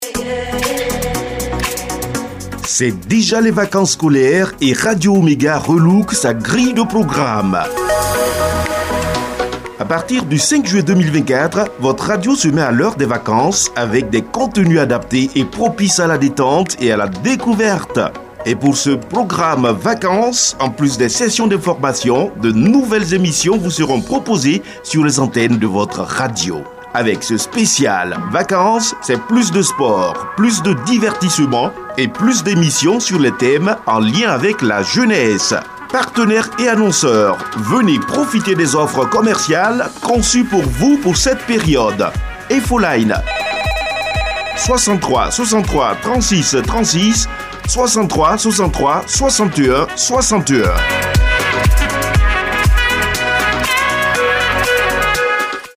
Sopt programme vacance 2024 de Radio Omega